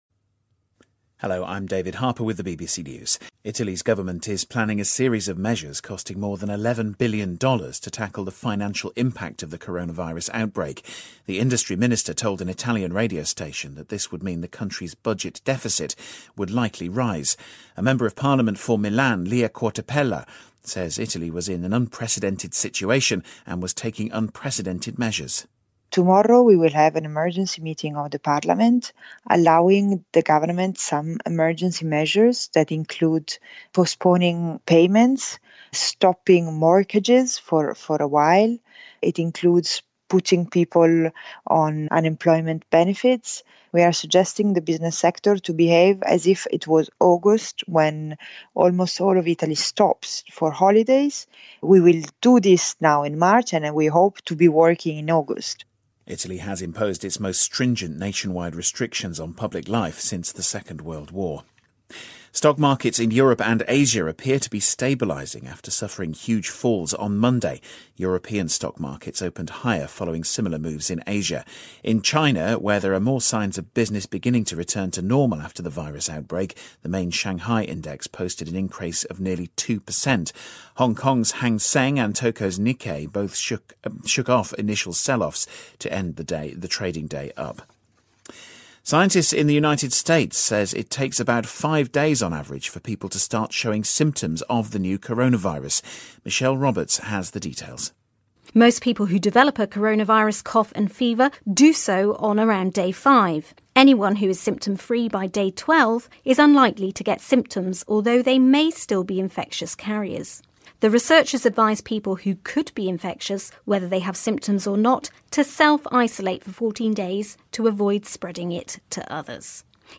News
英音听力讲解:美科学家研究发现新冠病毒平均潜伏期为5天